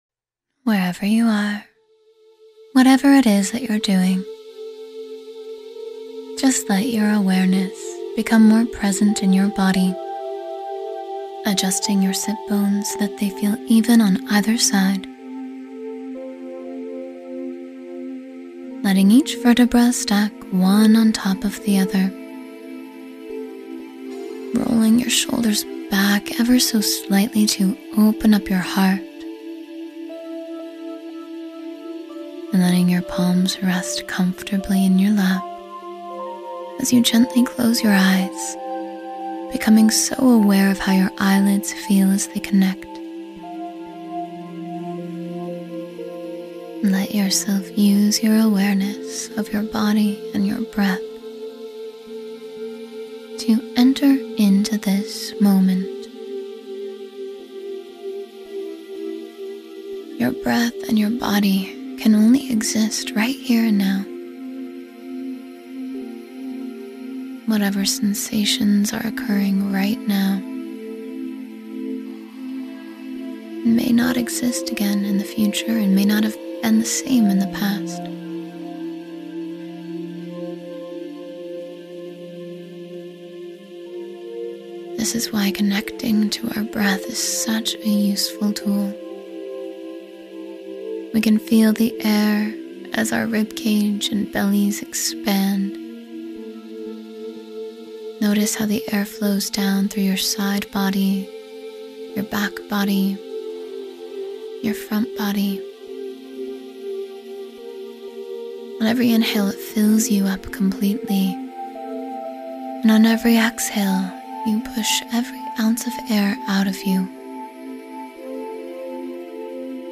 Self-Love, Forgiveness, and Acceptance — Meditation for Inner Peace